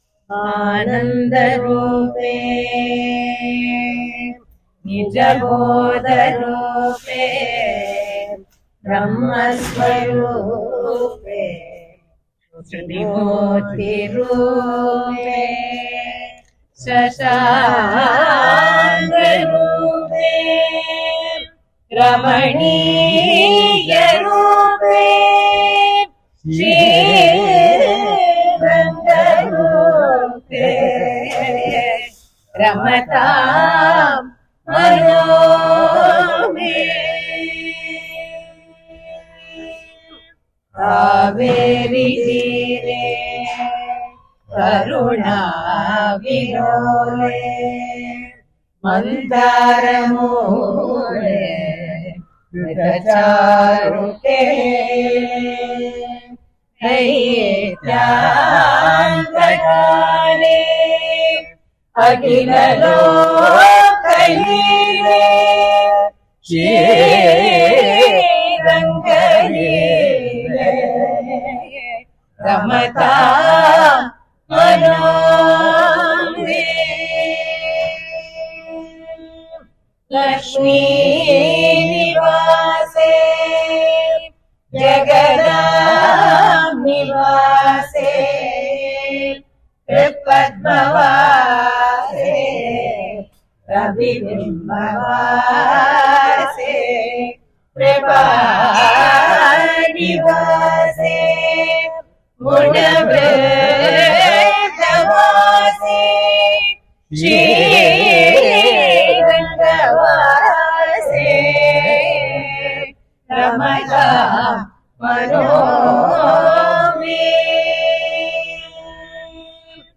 Due to the grace of Sri Thirumazhisai PirAn, the pAsurams of Thirucchanda viruttam were re-recorded on the 15th of January 2016 with background sruthi.